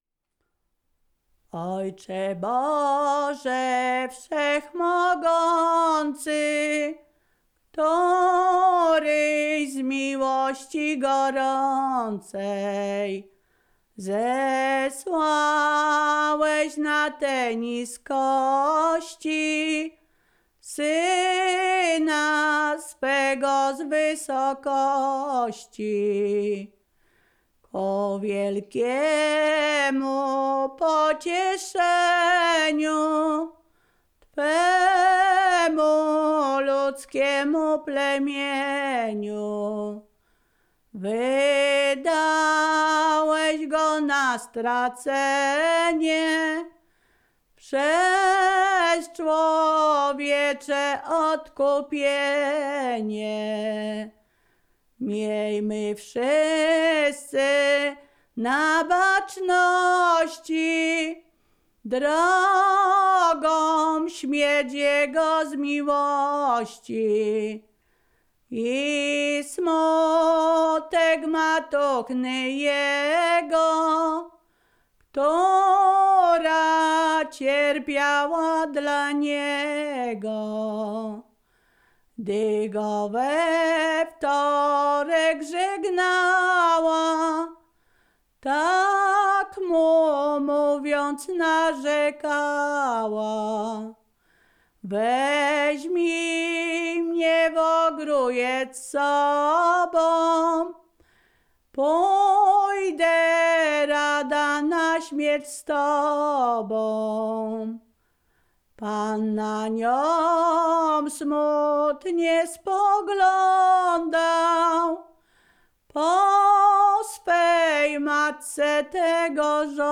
Ziemia Radomska
Wielkopostna
nabożne katolickie wielkopostne